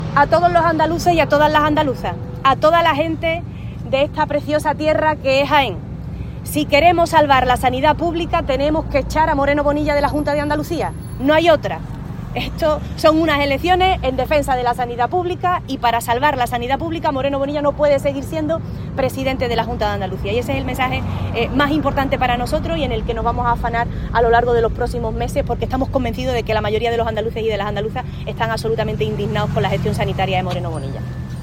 La responsable socialista hizo estas declaraciones a las puertas del Hospital Alto Guadalquivir de Andújar, que podría ser rebautizado como “Hospital de Alta Colocación del PP” por la cantidad de personas vinculadas a este partido que han encontrado puesto en este centro.